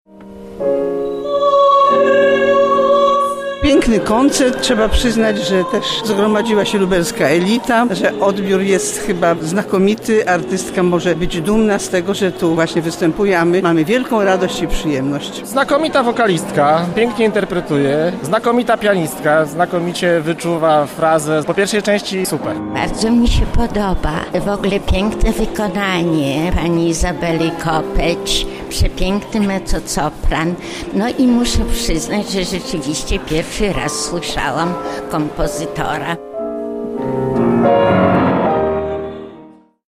Po wydarzeniu zapytaliśmy słuchaczy o wrażenia i program koncertu.